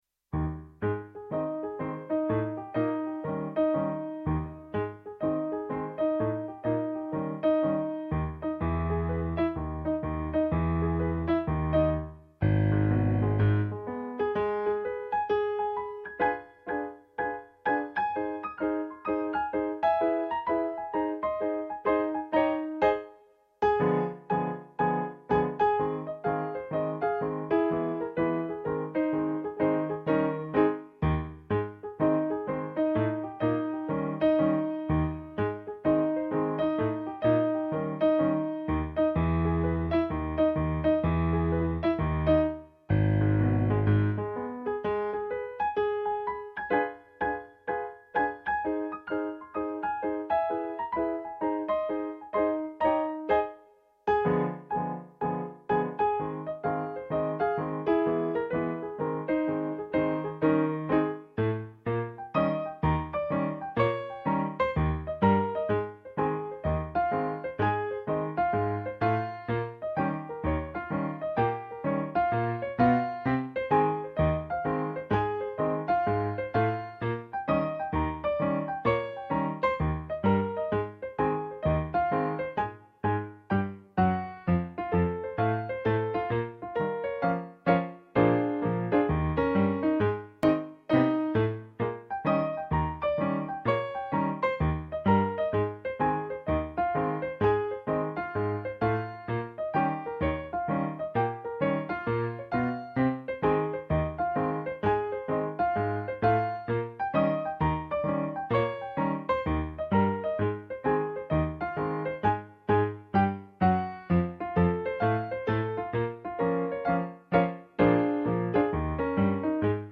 Ragtime
Si tratta ovviamente di esecuzioni fatte da un dilettante autodidatta quale sono io, ma animato da molta, moltissima passione per questo genere di musica.